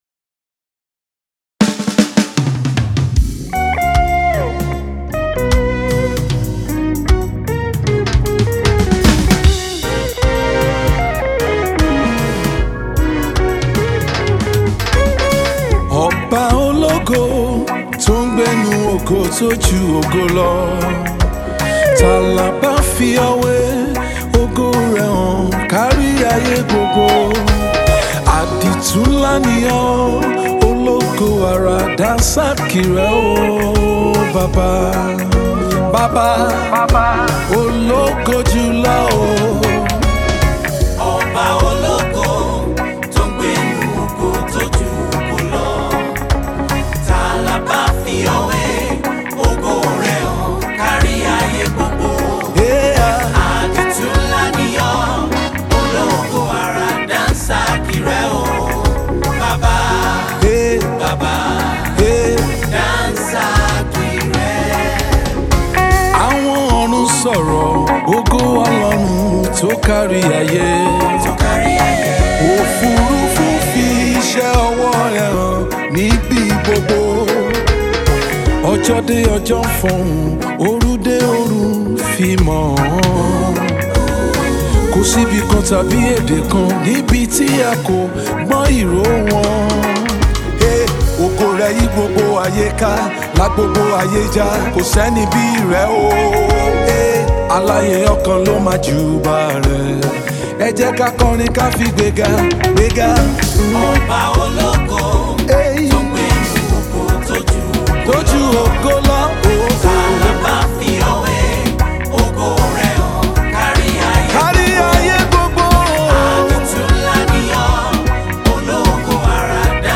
Gospel
an indigenous-flavored reggae track